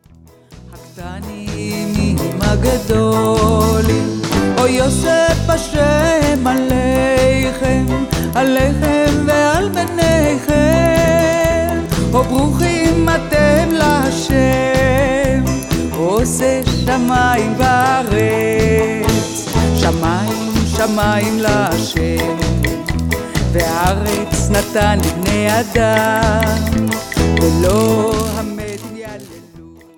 Folk